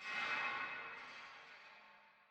ethereal_crystal.ogg